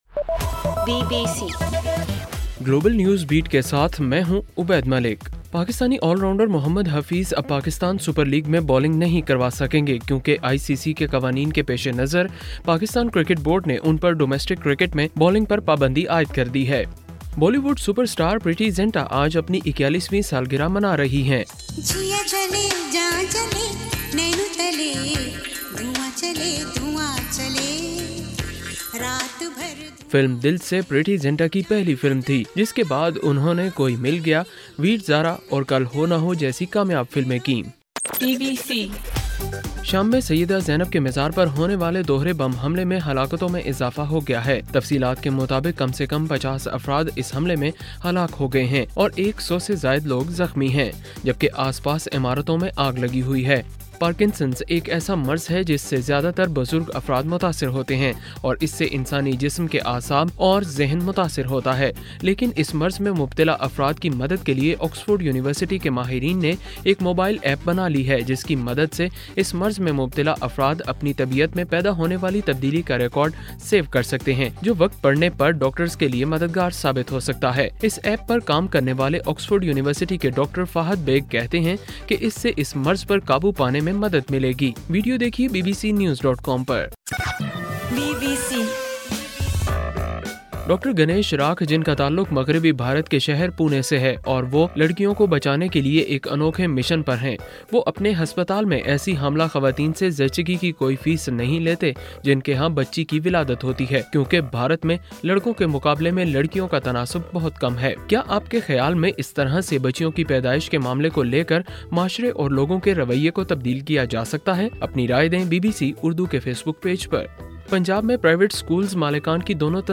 جنوری 31: رات 10 بجے کا گلوبل نیوز بیٹ بُلیٹن